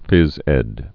(fĭz ĕd)